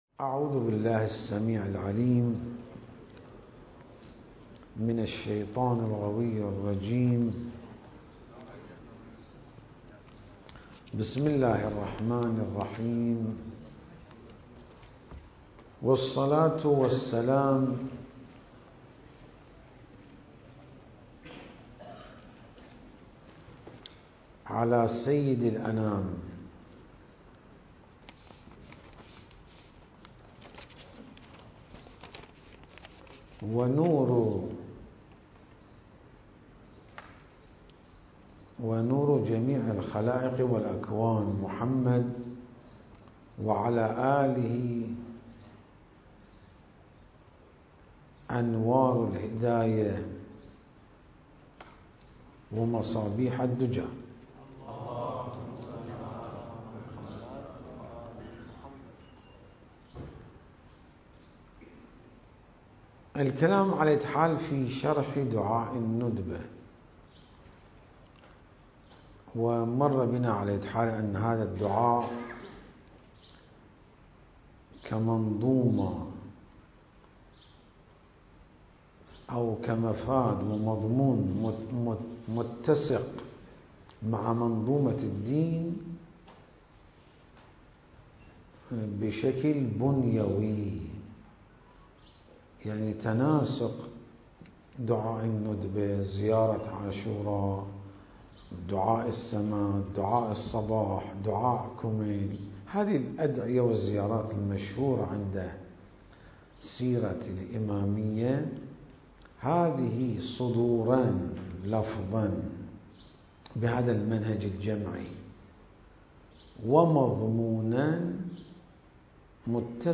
المكان: العتبة العلوية المقدسة